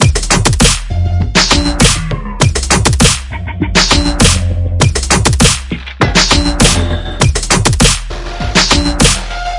Triphop /舞蹈/拍/嘻哈/毛刺跳/缓拍/寒意
Tag: 寒意 旅行 电子 舞蹈 looppack 样品 毛刺 节奏 节拍 低音 实验 器乐